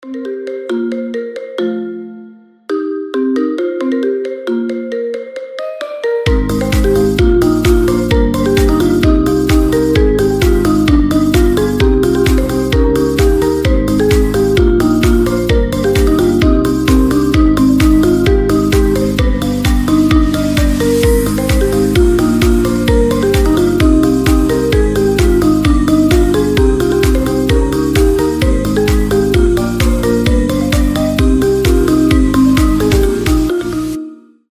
• Качество: 320, Stereo
мелодичные
без слов
японские
маримба
ремиксы
Айфон ремикс японской песни